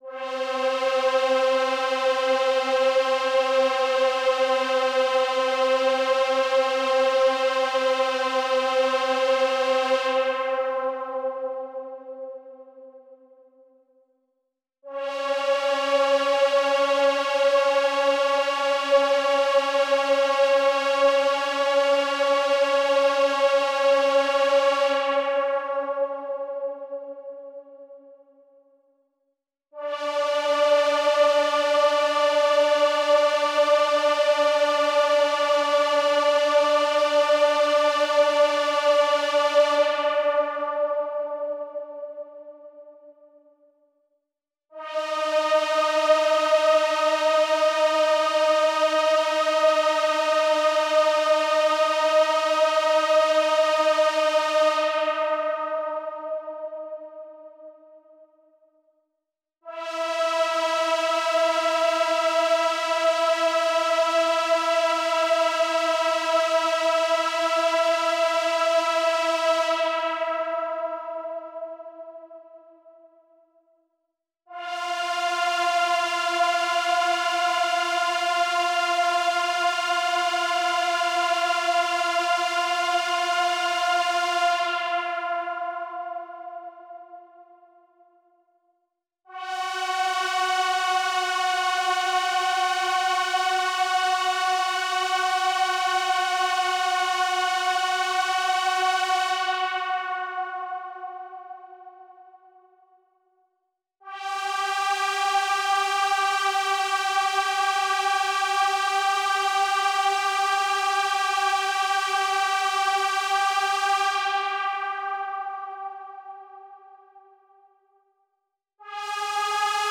M Big Pad.wav